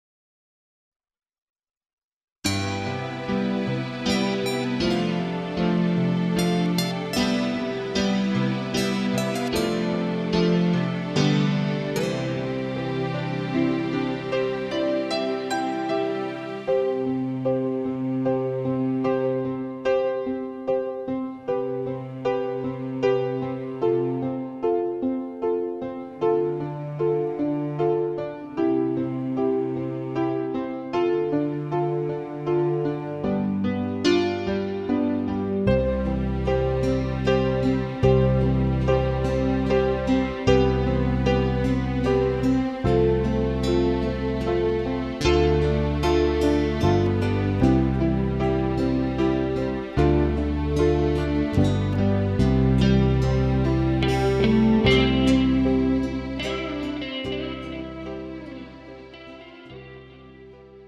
MR 고음질 반주 다운로드.